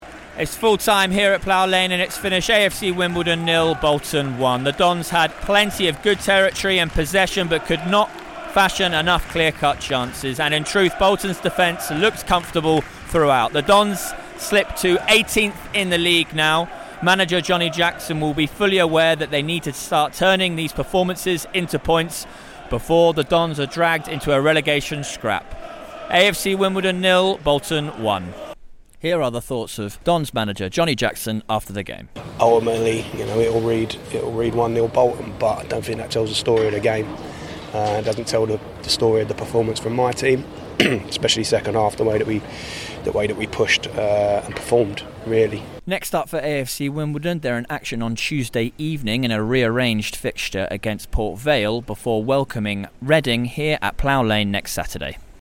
AFC Wimbledon post match report